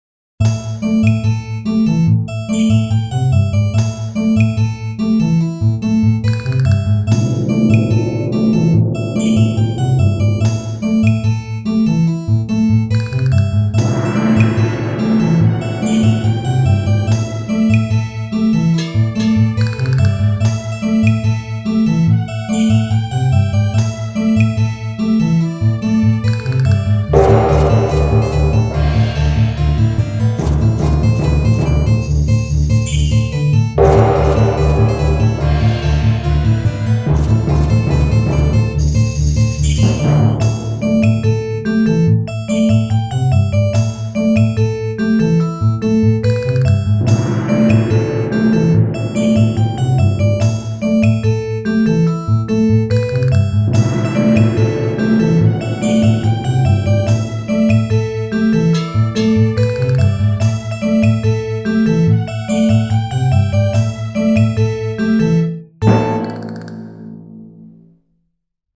Peu d'instrus, mais bien répartis dans l'espace, les cordes sont un peu dry sur le legato et avec une reverbe un peu trop forte sur le pizz (selon moi hein !). L'ensemble sonne très VGM, j'aime bien la répartition des percus sur la gauche, des accompagnements sur la droite et du thème bien centré.